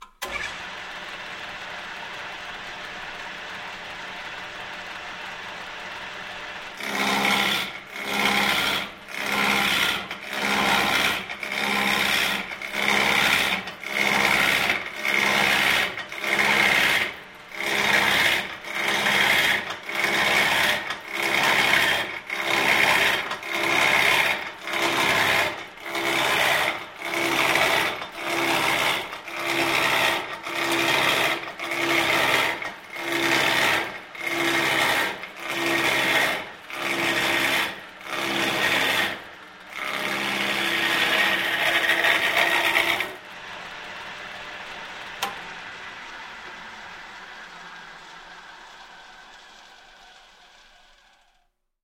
Звуки токарного станка
еще с деревом